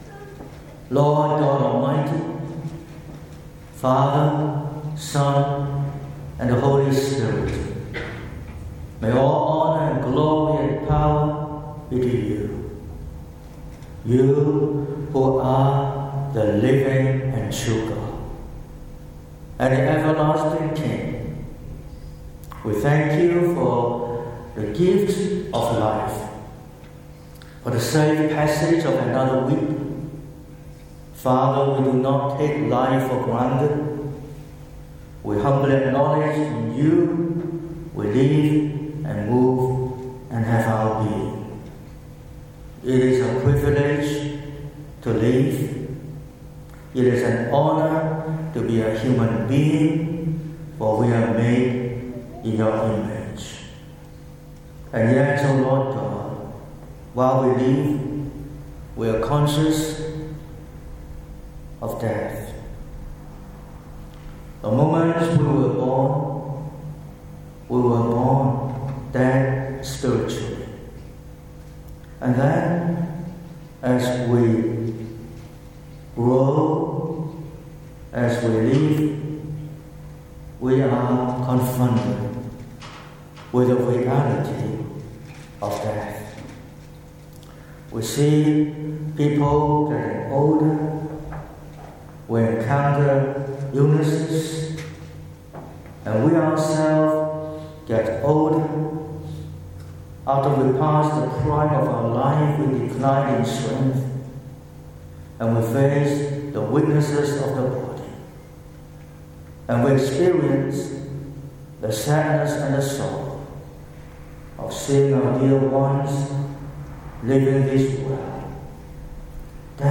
19/04/2026 – Morning Service: The Three Gardens